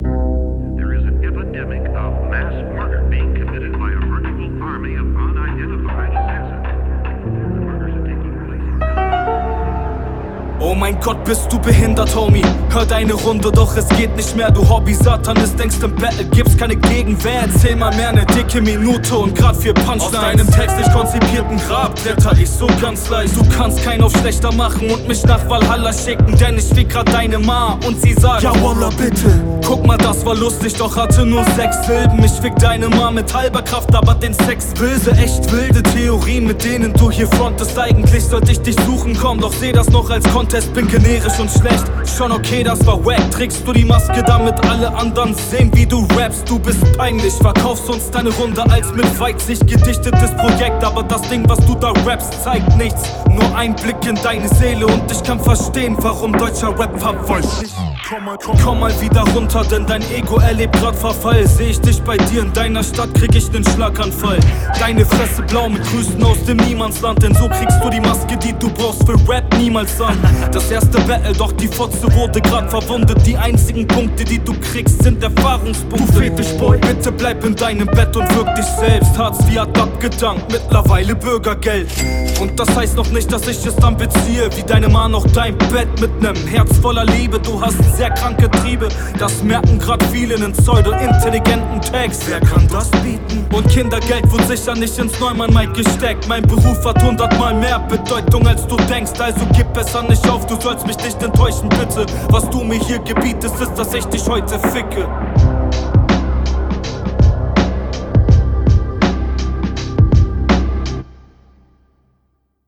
wenn man jede art von line richtig böse delivert, hat das irgendwann den gegenteiligen effekt, …